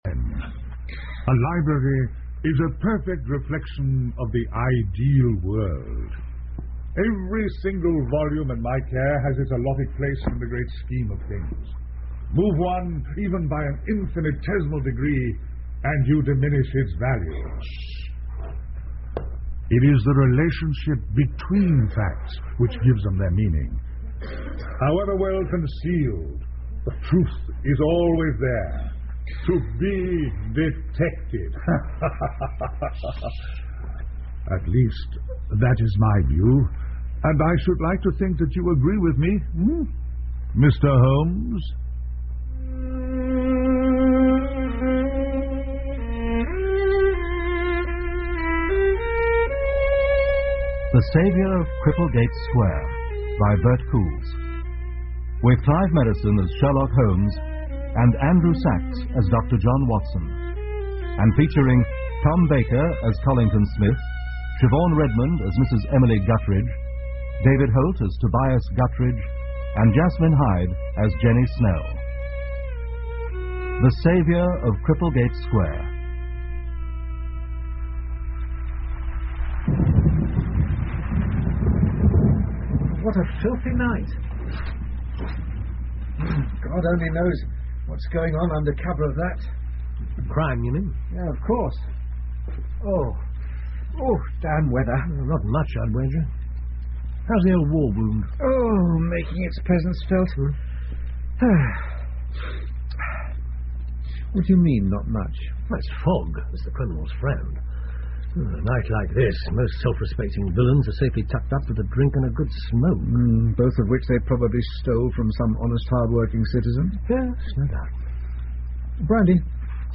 福尔摩斯广播剧 The Saviour Of Cripplegate Square 1 听力文件下载—在线英语听力室